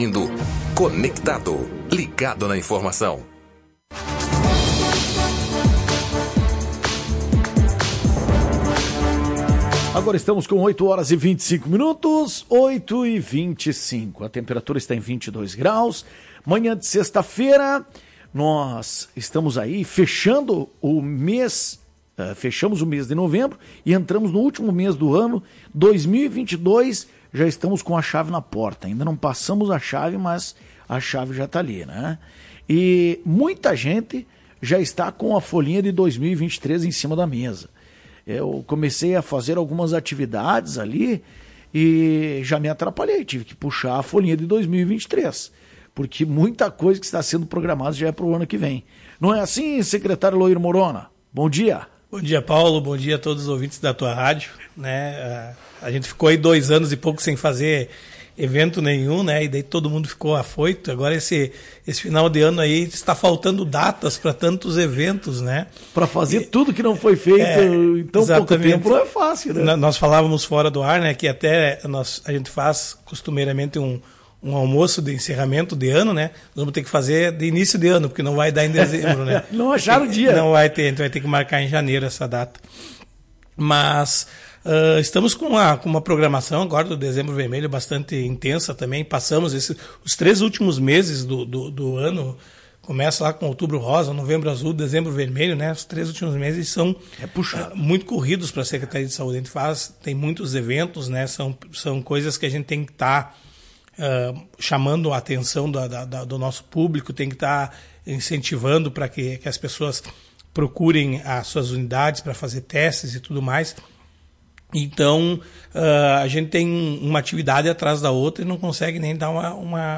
Em entrevista à Tua Rádio Cacique na manhã desta sexta-feira, 02 de dezembro, o secretário de Saúde de Lagoa Vermelha Eloir Morona tratou de diversos assuntos, como as sessões de Pilates gratuitas, casos de Covid e programação da secretaria no final de ano. O gestor divulgou também o cronograma do Ônibus Vermelho.